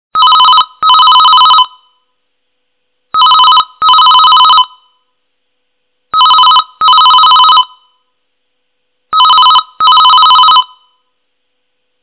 basic-phone-call_25531.mp3